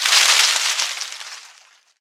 swamp_appear_1.ogg